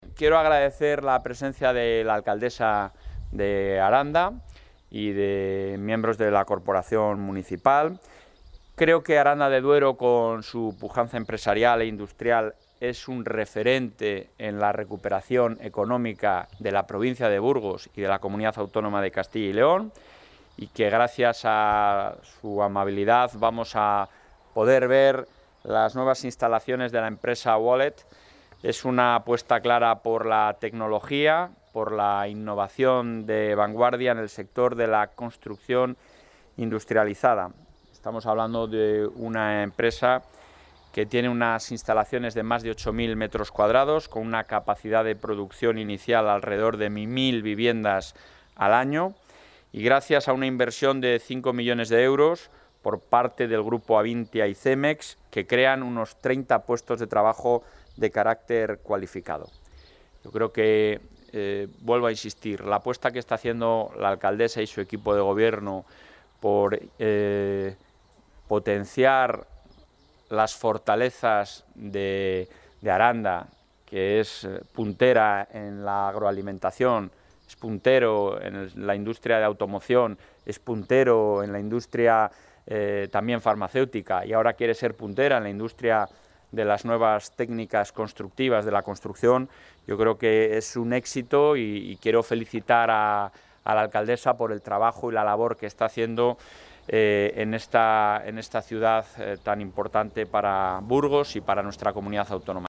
Material audiovisual de la visita del presidente de la Junta a la empresa Wallex
Valoración del presidente de la Junta.